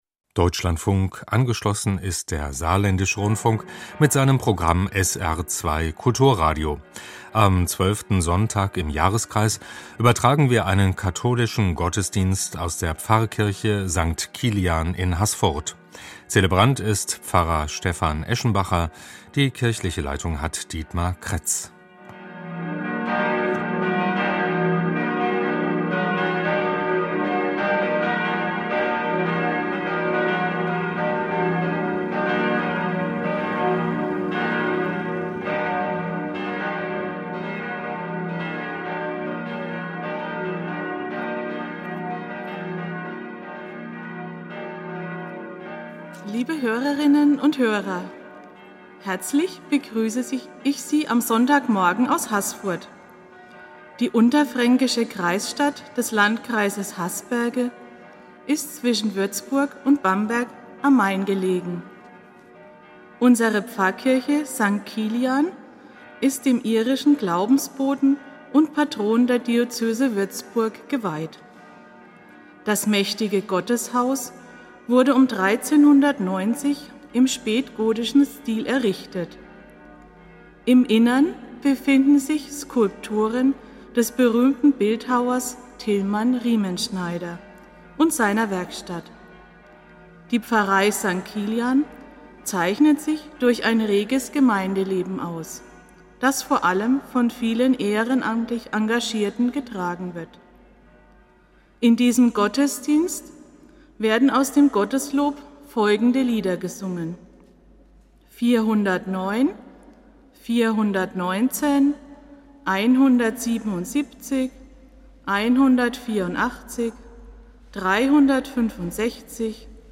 Katholischer Gottesdienst aus Haßfurt